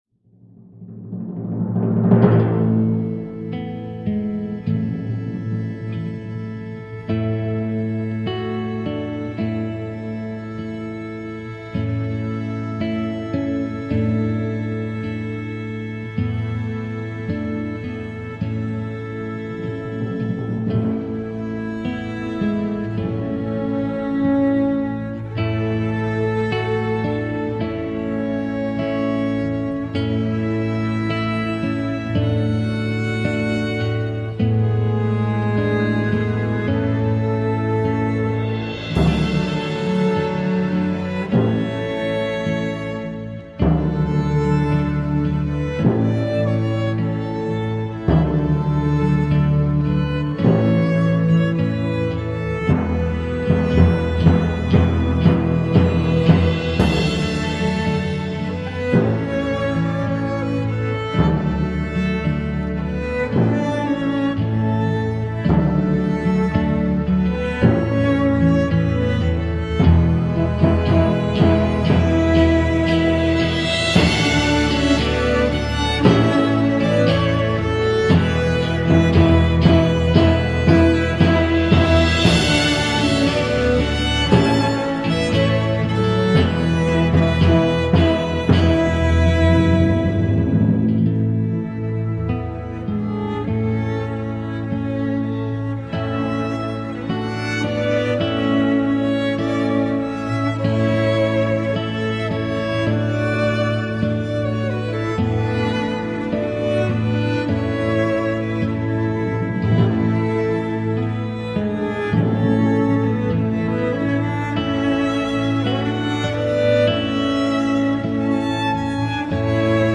Early music meets global folk at the penguin café.